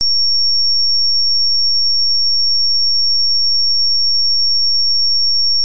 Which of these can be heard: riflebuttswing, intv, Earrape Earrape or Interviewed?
Earrape Earrape